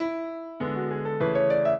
piano
minuet5-7.wav